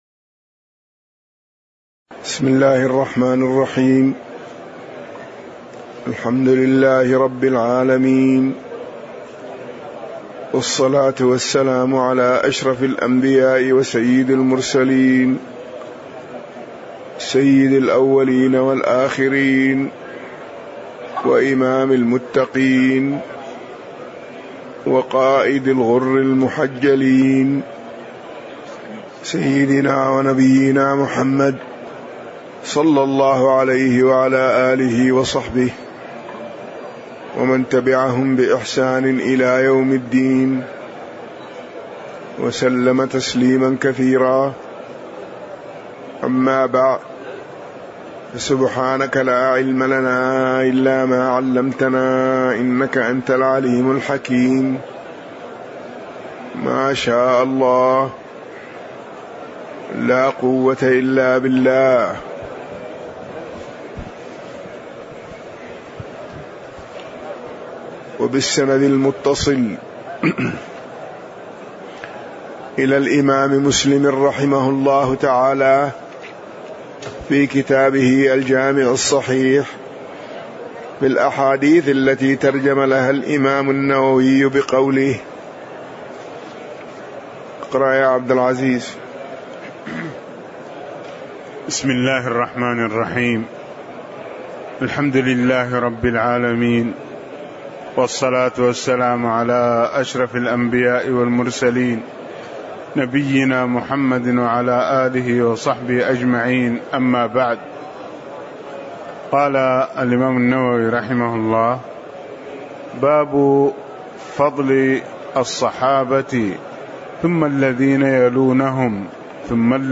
تاريخ النشر ١٤ ذو القعدة ١٤٣٧ هـ المكان: المسجد النبوي الشيخ